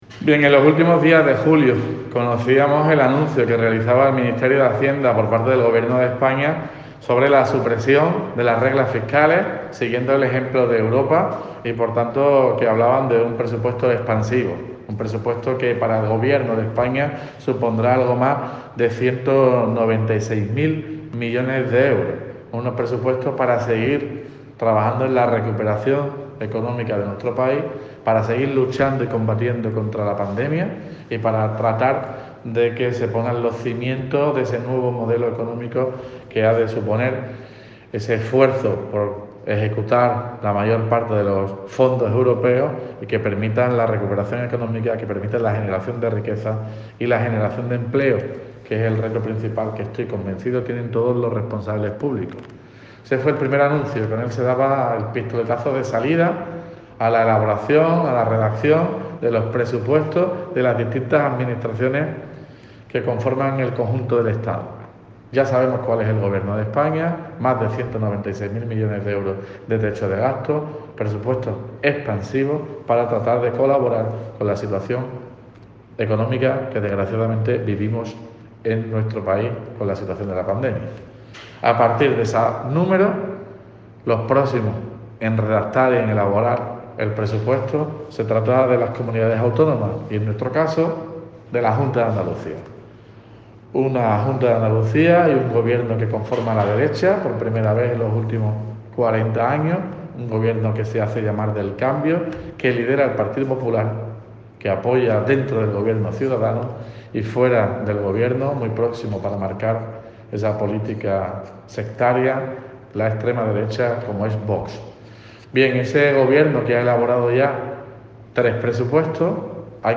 PRESUPUESTOS JUNTA TOTAL ALCALDE.mp3